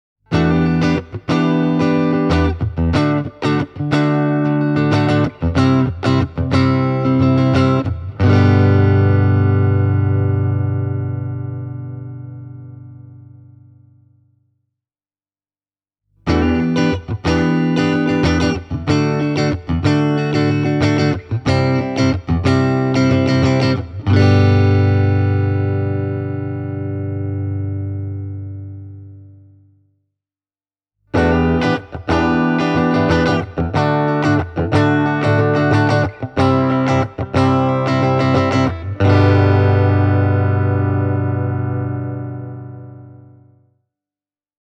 fender-am-pro-telecaster-e28093-tweed-clean.mp3